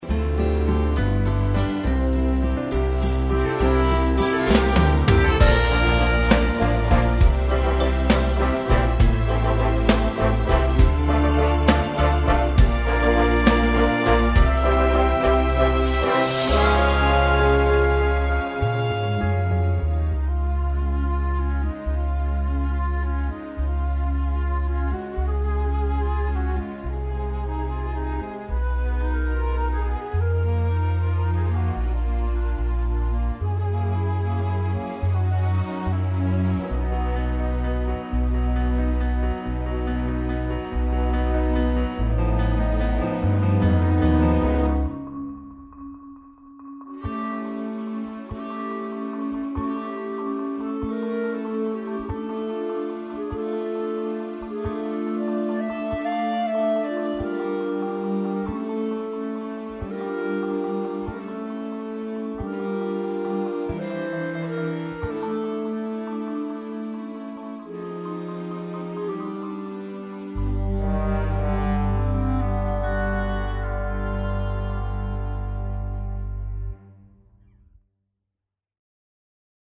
Playbacks und Karaoketitel
Pop
Instrumental
backing track